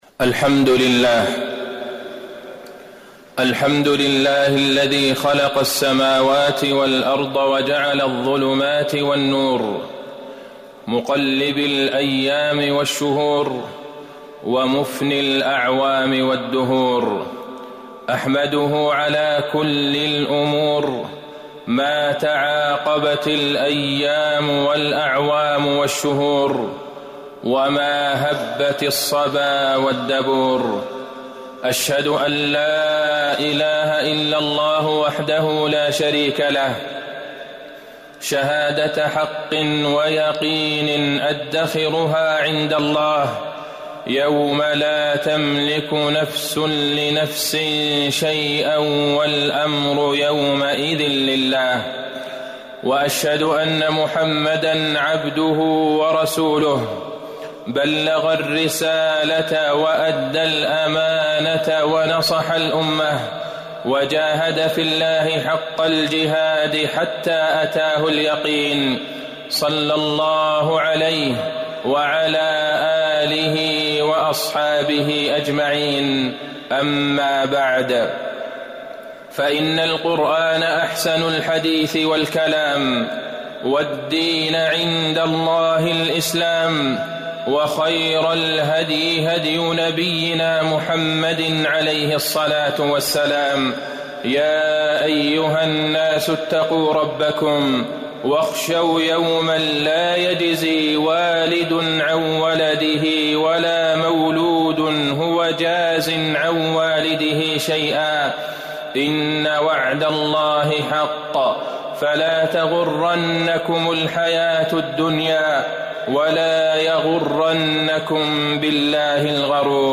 تاريخ النشر ١٤ شعبان ١٤٤٠ هـ المكان: المسجد النبوي الشيخ: فضيلة الشيخ د. عبدالله بن عبدالرحمن البعيجان فضيلة الشيخ د. عبدالله بن عبدالرحمن البعيجان فضائل شعبان والتهنئة لاستقبال رمضان